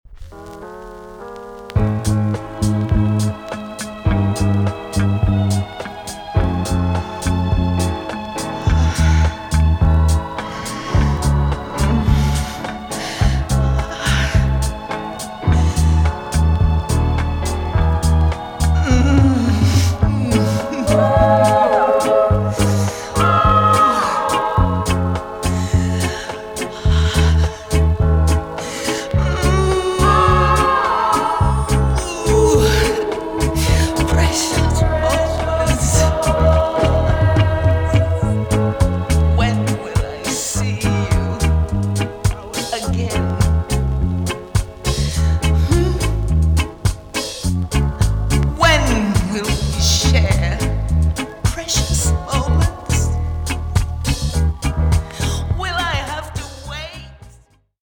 TOP >REGGAE & ROOTS
EX- 音はキレイです。